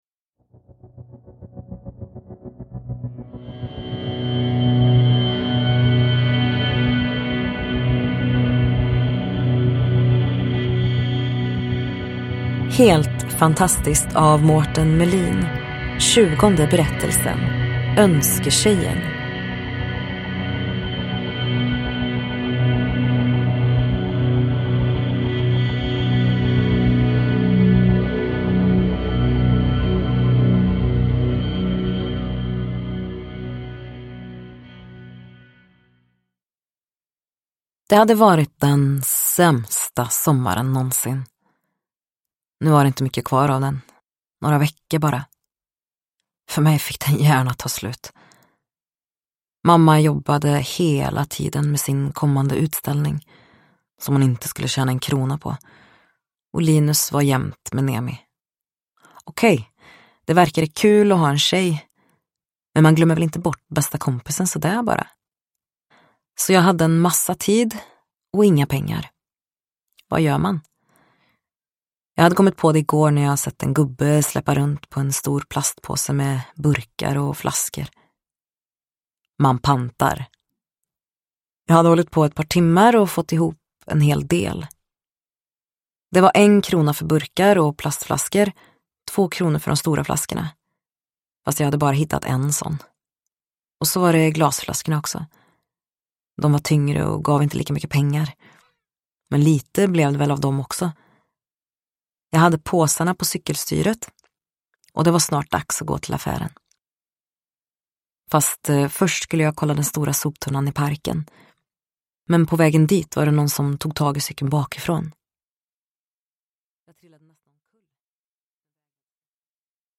Önsketjejen : en novell ur samlingen Helt fantastiskt – Ljudbok – Laddas ner